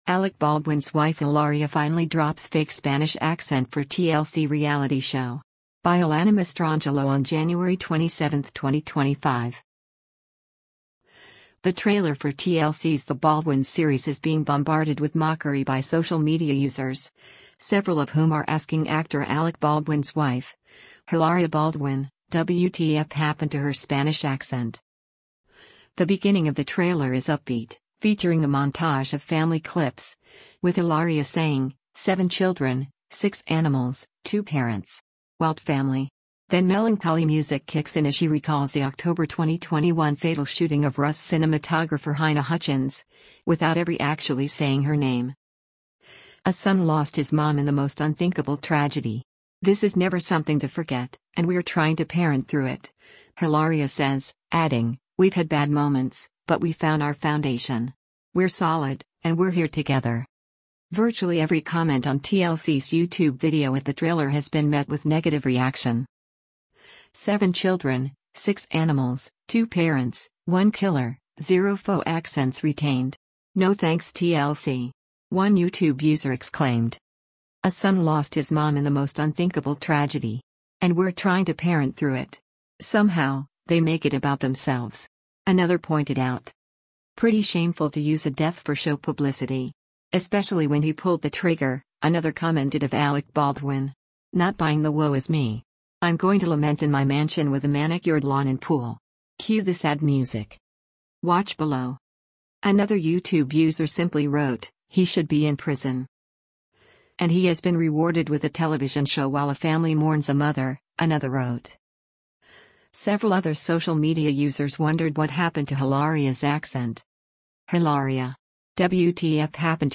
Alec Baldwin's Wife Hilaria Finally Drops Fake Spanish Accent for TLC Reality Show
The beginning of the trailer is upbeat, featuring a montage of family clips, with Hilaria saying, “Seven children, six animals, two parents… wild family.” Then melancholy music kicks in as she recalls the October 2021 fatal shooting of Rust cinematographer Halyna Hutchins — without every actually saying her name.
“Wow all of a sudden she doesn’t have an accent!” another exclaimed.